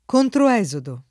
[ kontro $@ odo ]